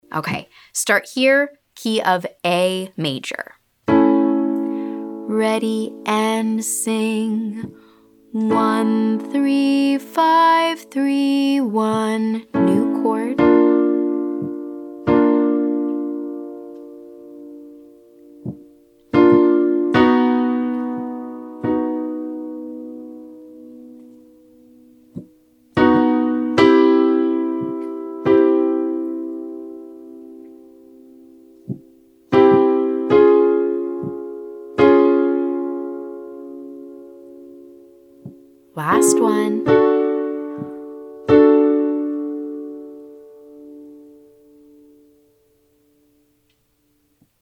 I’ll play the chord this time, but not the arpeggio, meaning you’ll hear those three pitches all at once, and it’s up to you to extract the individual pitches to sing.
Exercise/quiz: 13531 - piano plays chord & student sings arpeggio